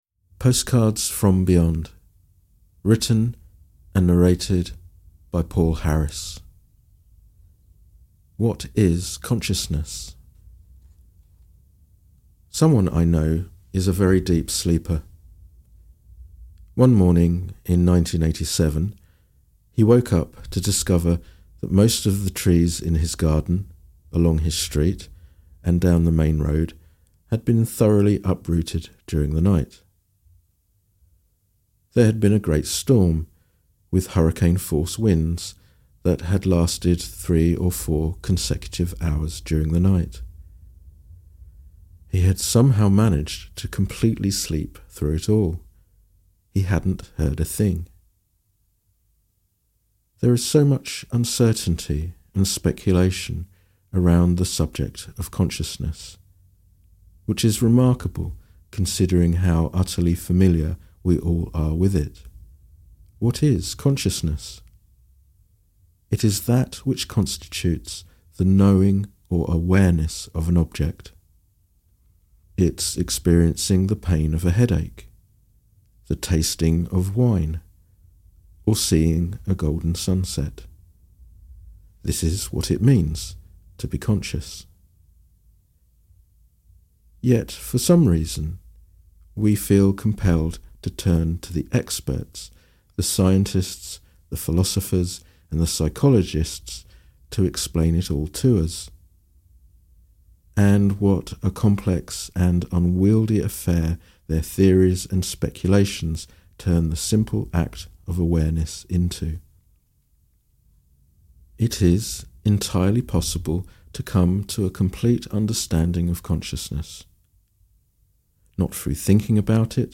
Audio recording of the book